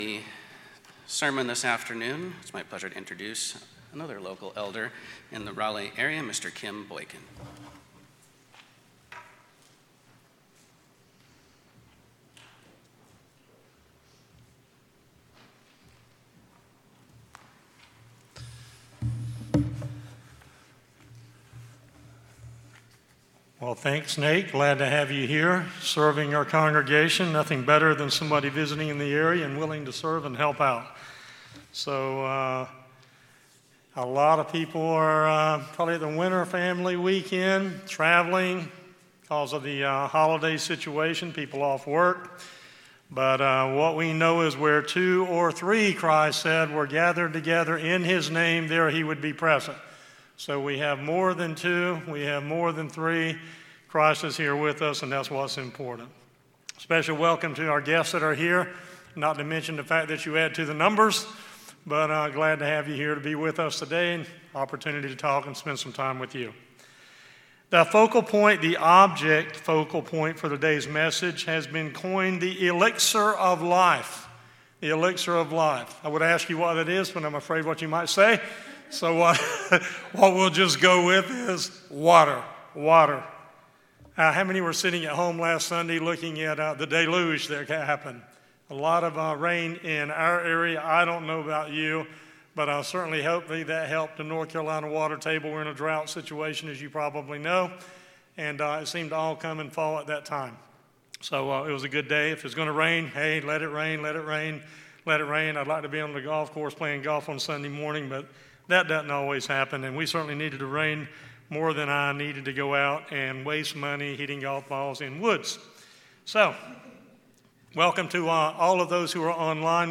Sermons Rivers
Given in Raleigh, NC